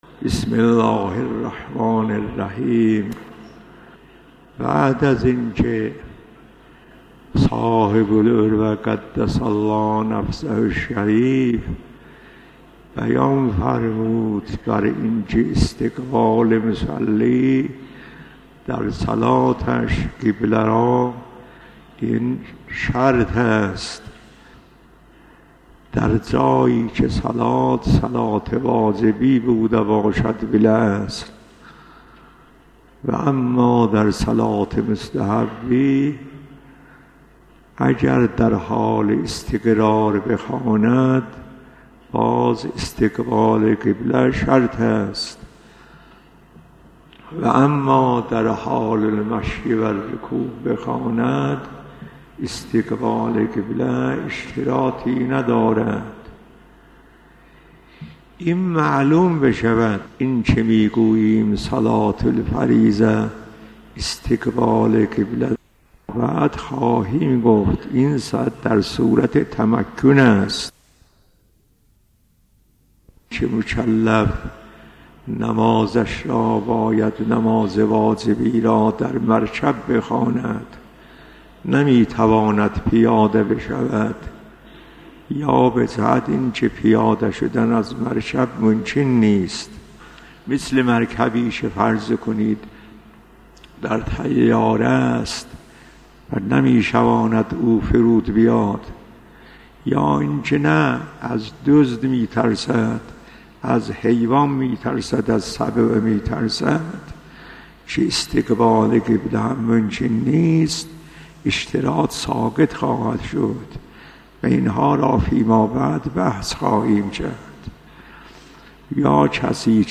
آیت الله جواد تبريزي - صلاة | مرجع دانلود دروس صوتی حوزه علمیه دفتر تبلیغات اسلامی قم- بیان